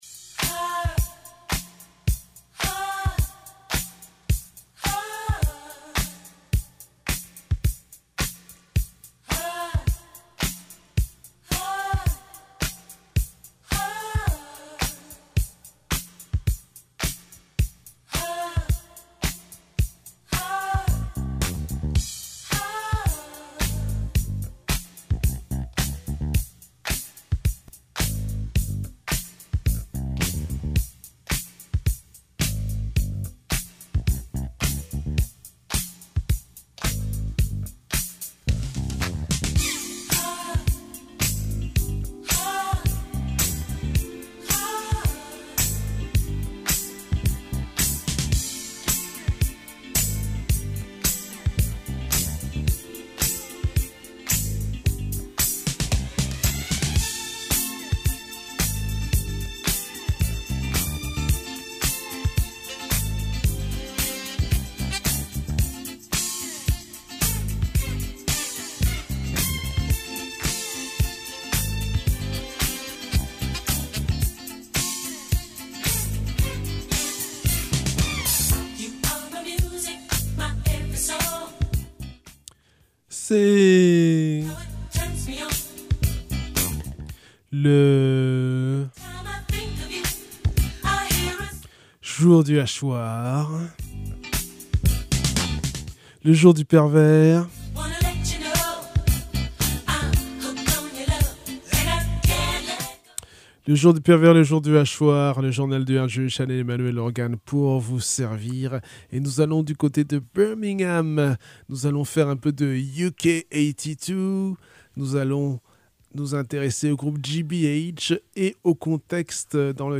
on s’attardera aussi sur une partie de la scène hardcore